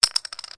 chip1.mp3